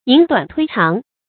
引短推長 注音： ㄧㄣˇ ㄉㄨㄢˇ ㄊㄨㄟ ㄔㄤˊ 讀音讀法： 意思解釋： 謂有意不露才以形己之短，顯人之長。